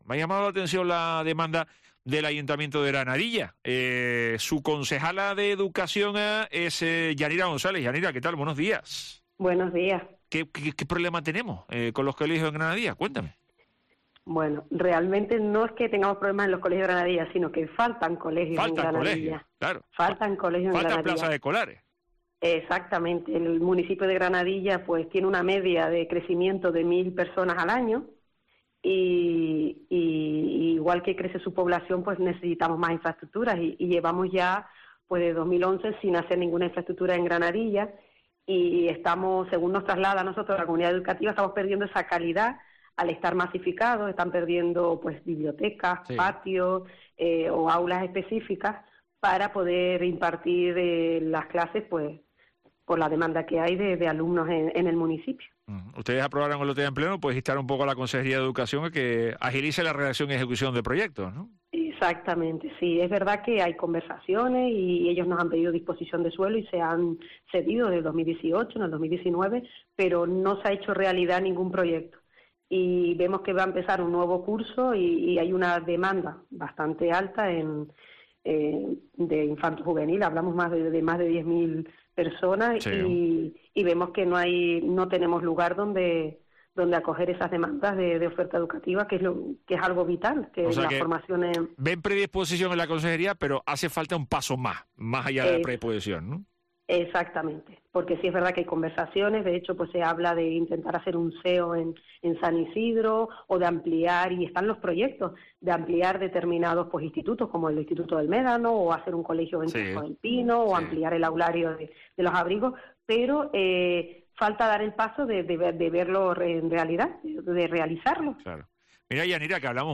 Entrevista a Yanira González, concejala de Educación de Granadilla (29/4/2022)